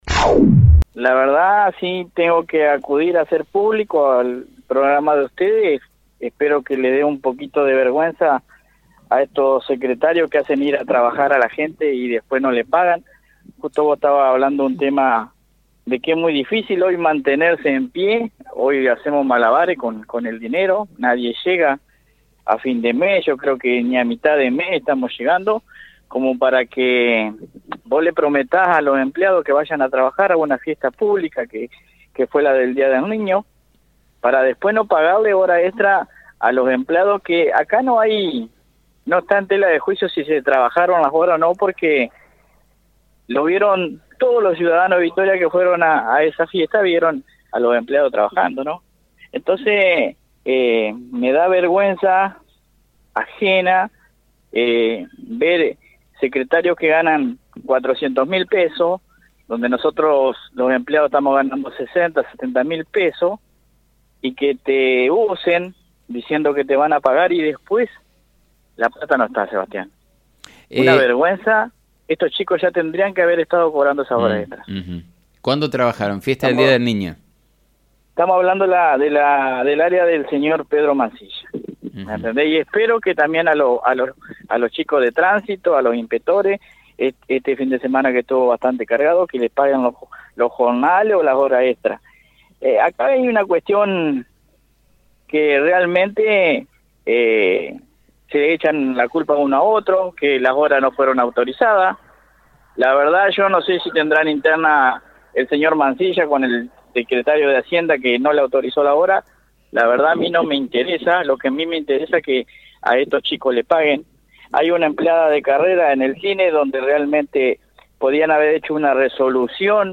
en diálogo con FM 90.3 hizo público el reclamo del pago de horas extra de los empleados municipales que trabajaron en la fiesta del Día del Niño.